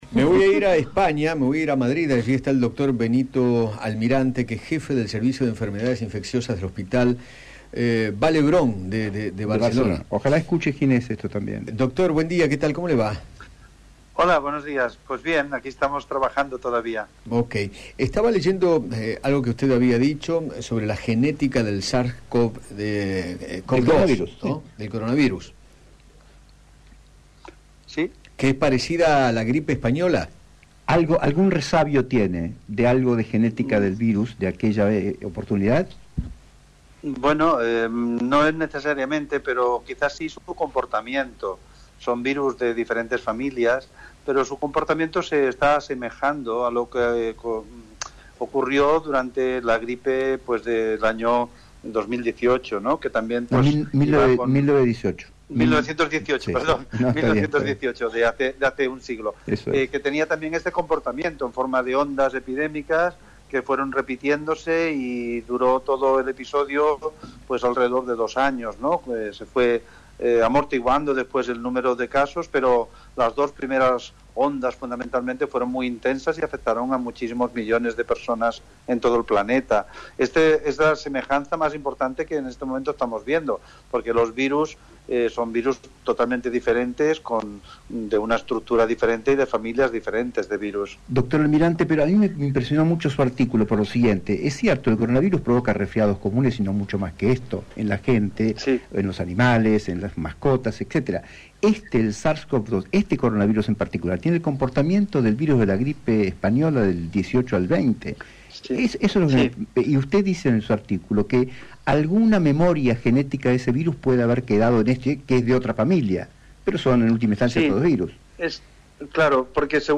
dialogó con Eduardo Feinmann